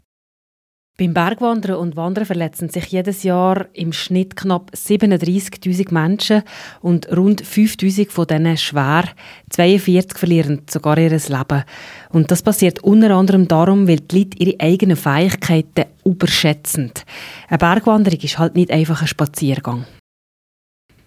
O-Ton zum Download